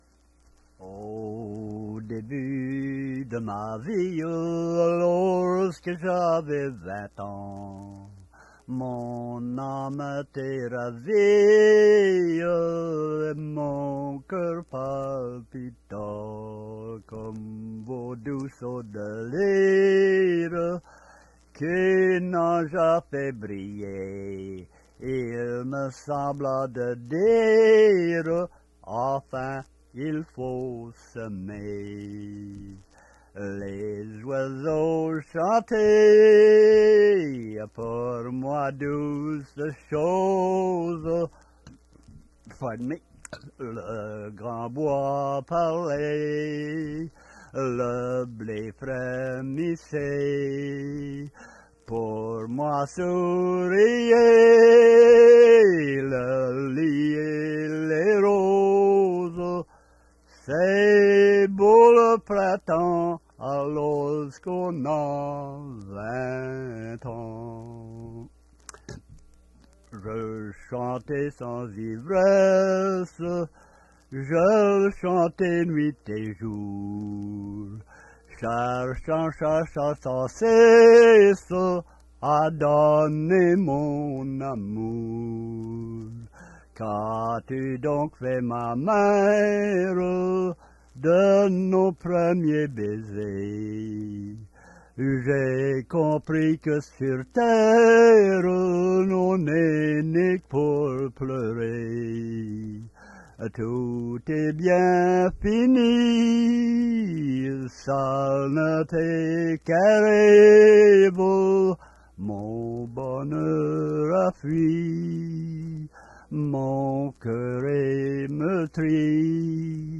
Chanson Item Type Metadata